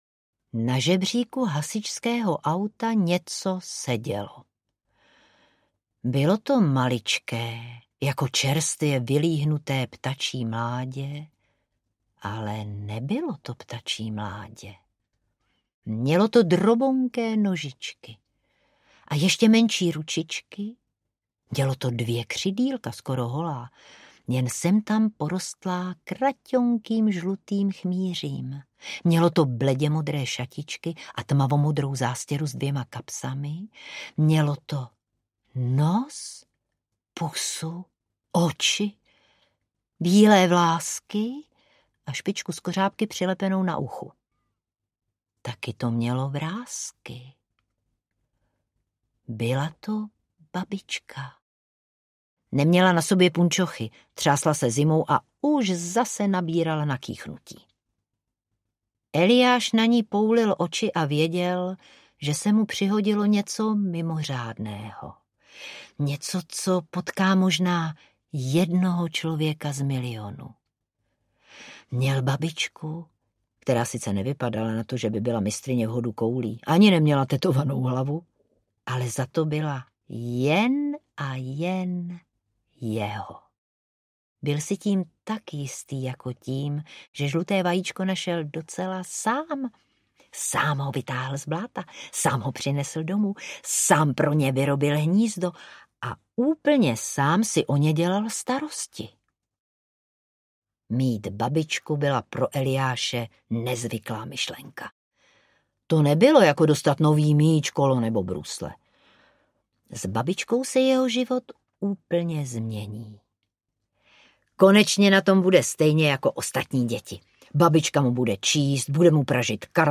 Eliáš a babička z vajíčka audiokniha
Ukázka z knihy
Vyrobilo studio Soundguru.